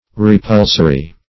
Repulsory \Re*pul"so*ry\ (-s?-r?), a.